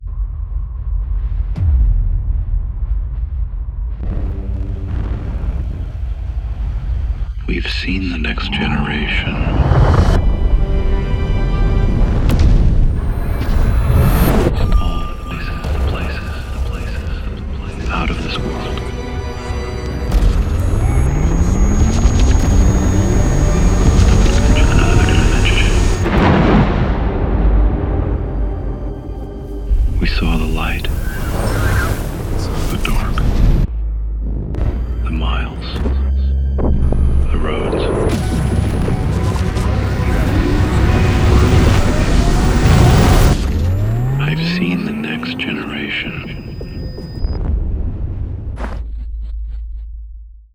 sound design
voice-over